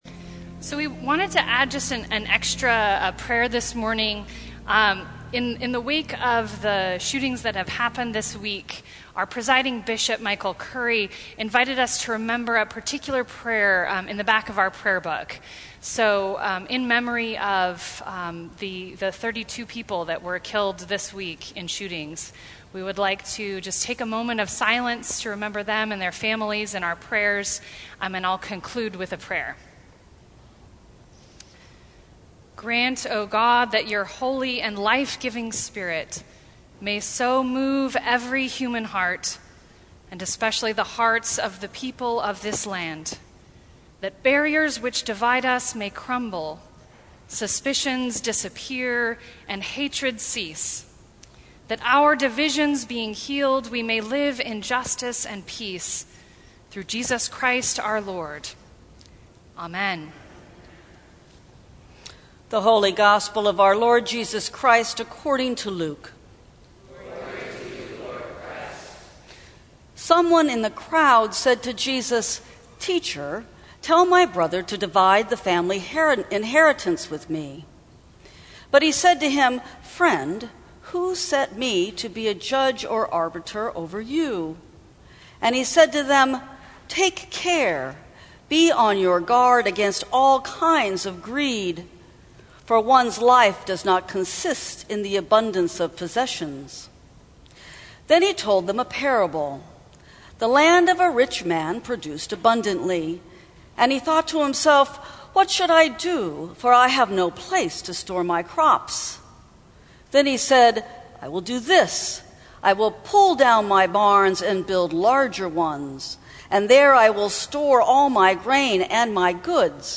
Sermons from St. Cross Episcopal Church Enough Aug 04 2019 | 00:19:06 1x 00:00 / 00:19:06 Subscribe Share Apple Podcasts Spotify Overcast RSS Feed Share Link Embed